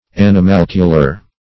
Search Result for " animalcular" : The Collaborative International Dictionary of English v.0.48: Animalcular \An`i*mal"cu*lar\, Animalculine \An`i*mal"cu*line\, a. Of, pertaining to, or resembling, animalcules.
animalcular.mp3